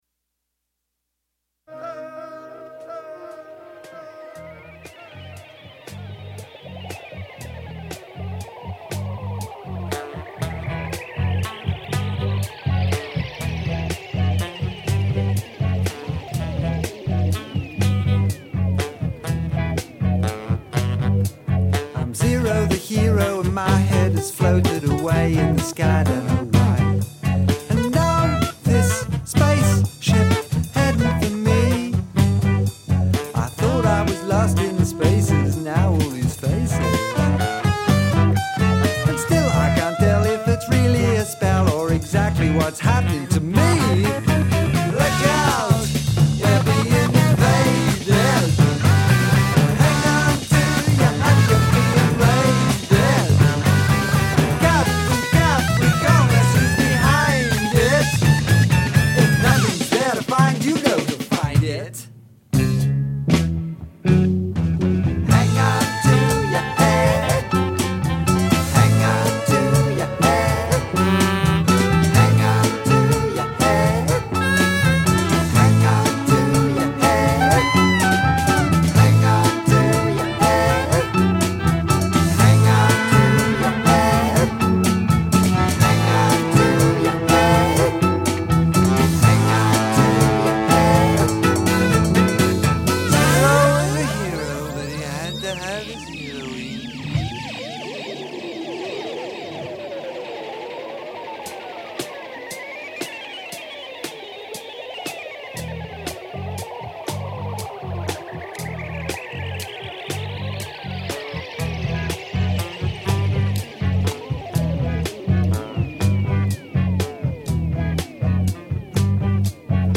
singer, song-writer and guitar player.